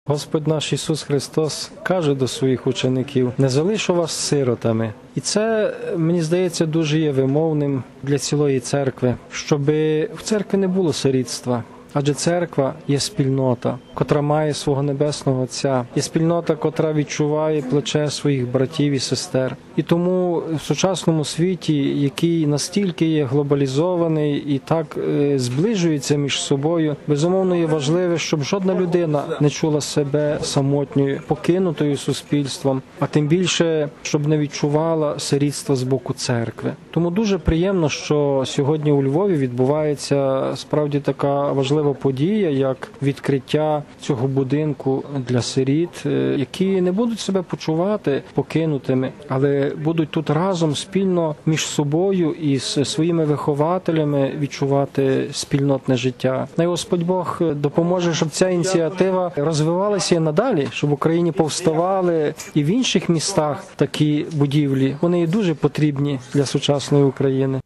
У неділю, 1 червня 2014 р., будинок посвятив екзарх Луцький УГКЦ владика Йосафат Говера, який інтерв’ю для Радіо Ватикану сказав: RealAudio